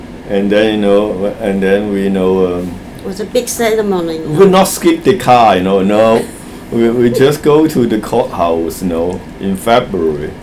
S1 = Taiwanese female S2 = Hong Kong male Context: S2 is talking about his simple wedding in the USA.
Intended Words : class Heard as : car Discussion : There is no [l] in class , and there is no [s] at the end of the word.